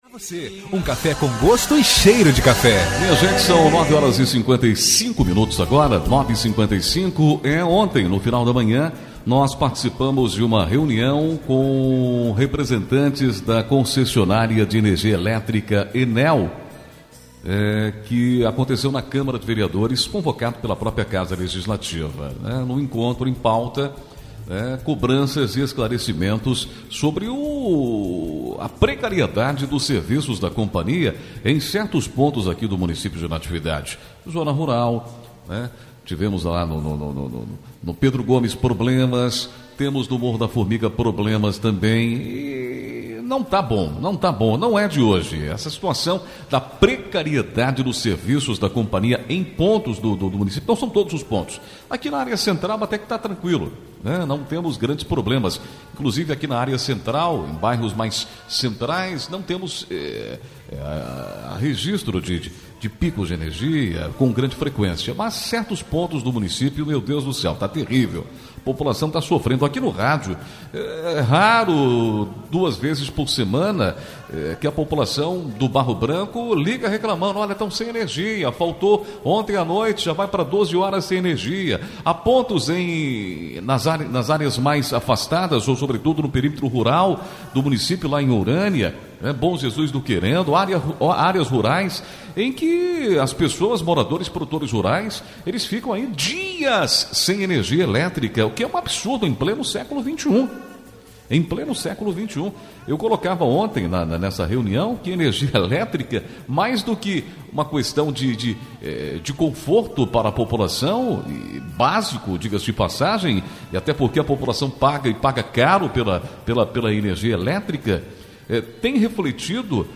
A precariedade dos serviços oferecidos pela concessionária ENEL em Natividade, foi tema de reunião no final da manhã desta quarta-feira (26), entre representantes da companhia, vereadores e o poder executivo.
REUNIAO-ENEL.mp3